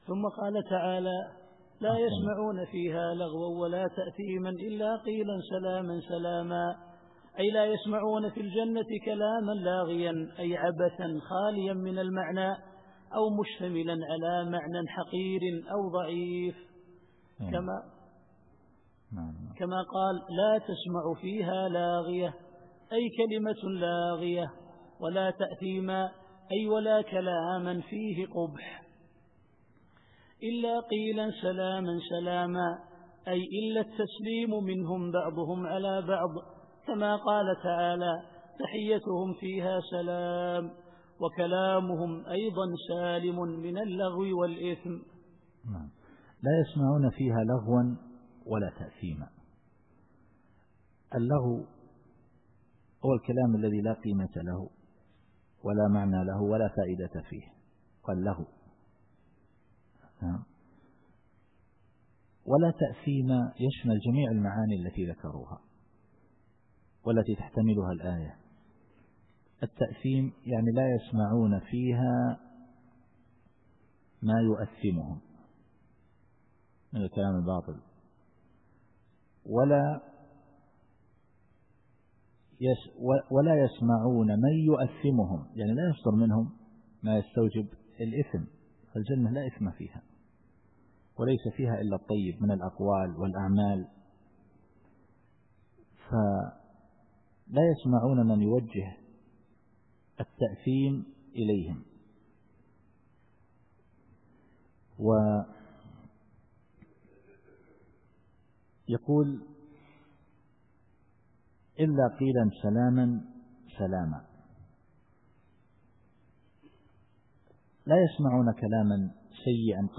التفسير الصوتي [الواقعة / 25]